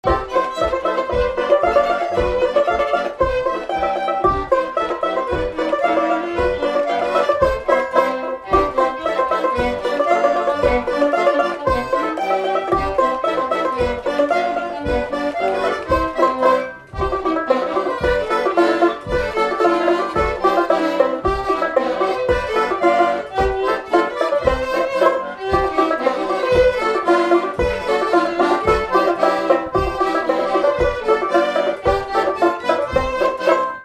Mémoires et Patrimoines vivants - RaddO est une base de données d'archives iconographiques et sonores.
Polka
Instrumental
danse : polka